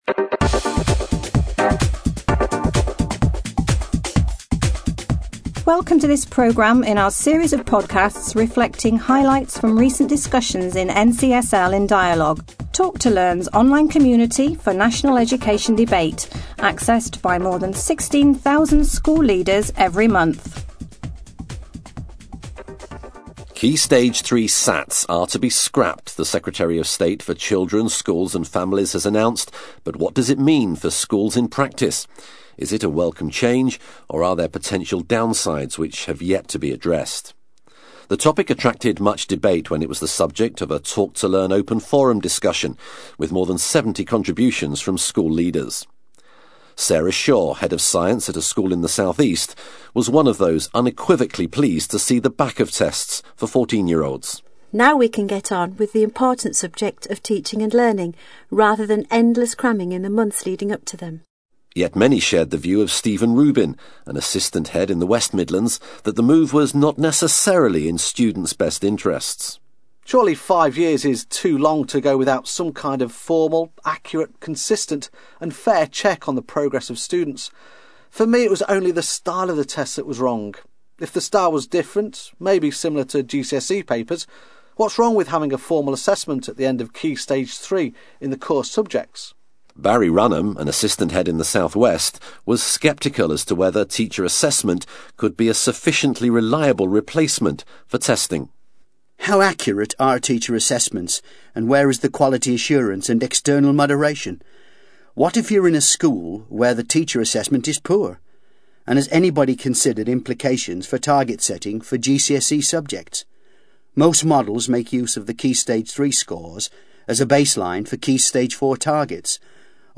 Two school leaders in conversation